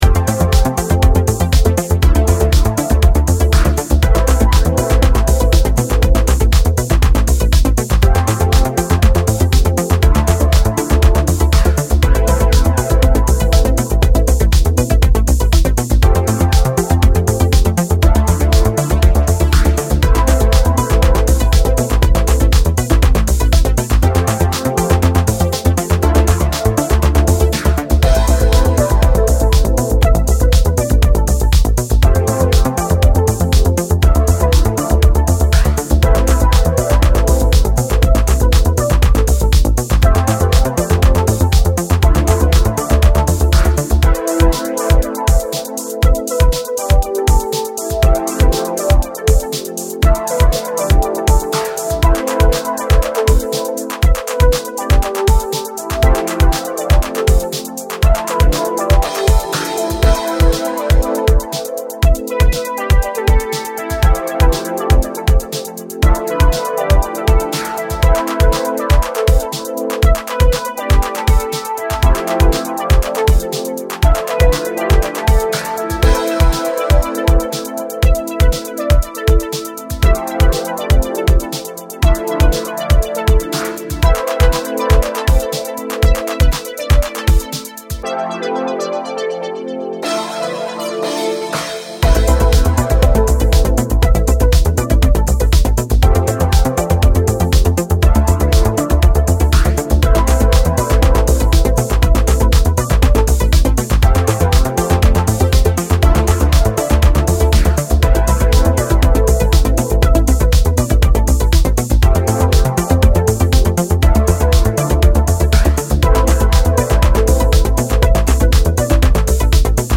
classic sounds and intellectual deep house.